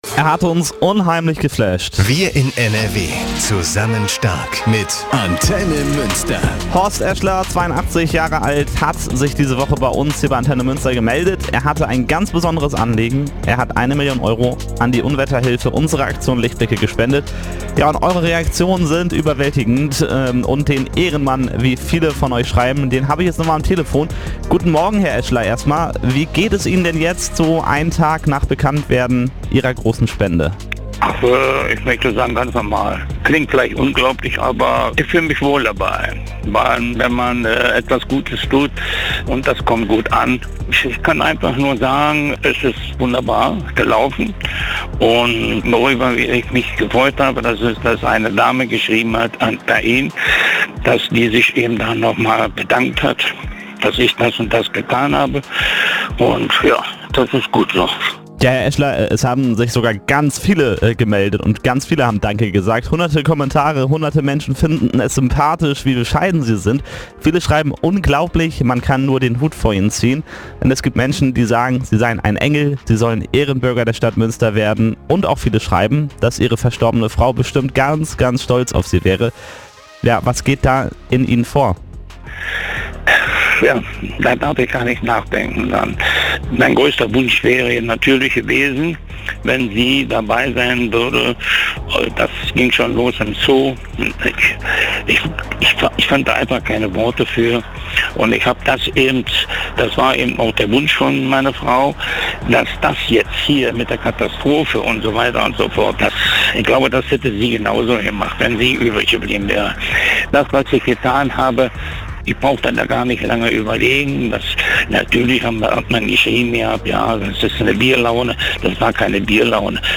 Er sagt im ANTENNE MÜNSTER-Interview: